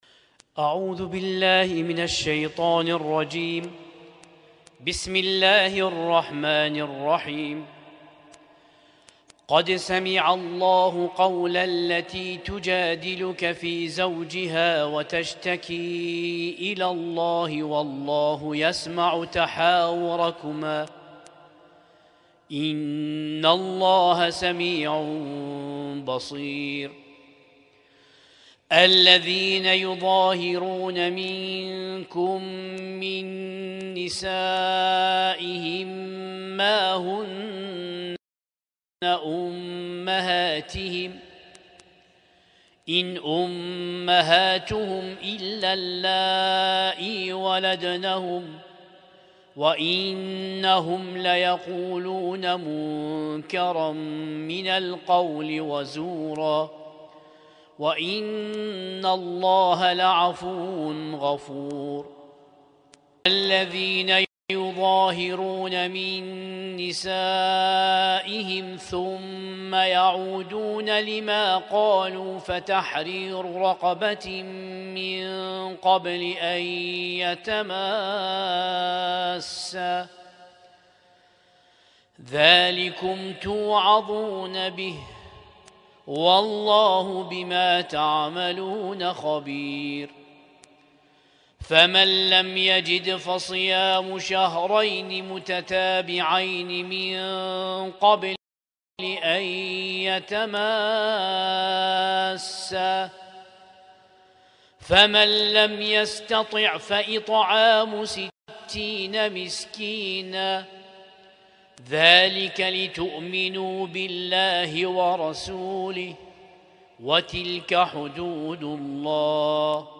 ليلة 25 من شهر رمضان 1447هـ